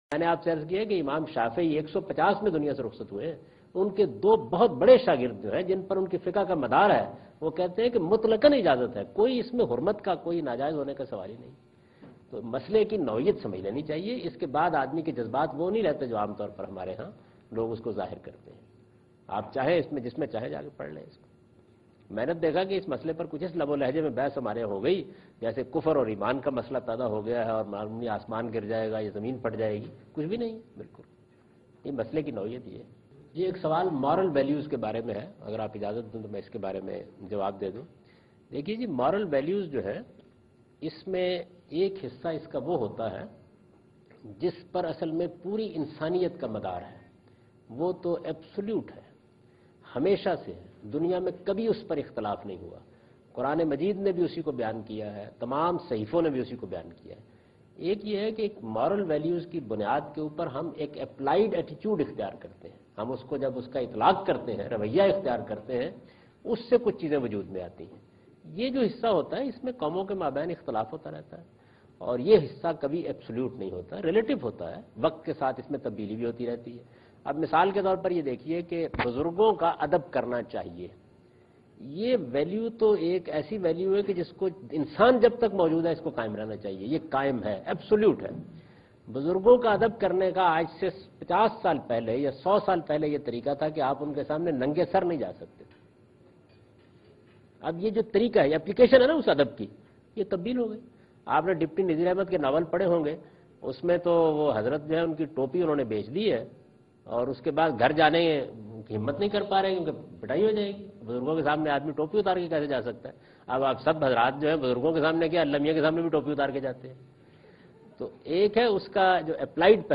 بیان قرآن کی اس کے سیاق و سباق اور ترتیب سے متعلق ایک پر مغرز گفتگو۔